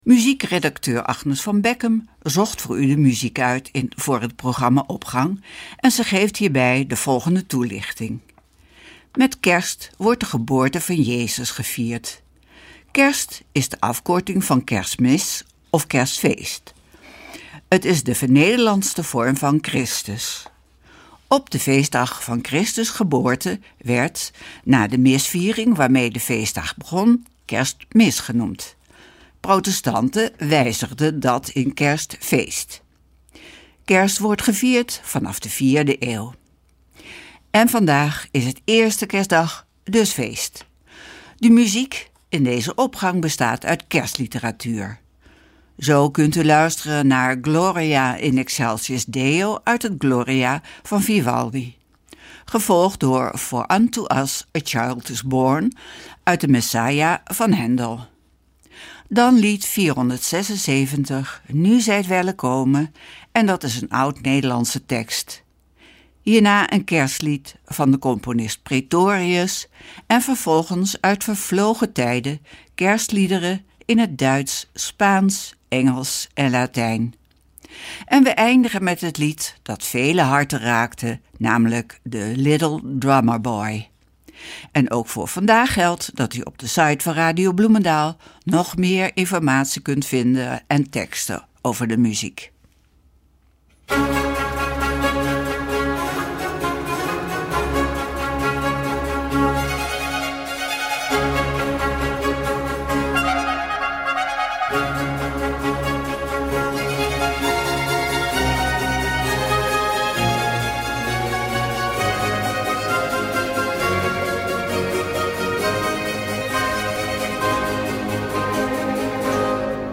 Opening van deze zondag met muziek, rechtstreeks vanuit onze studio.
De muziek in deze Opgang bestaat uit kerstliteratuur.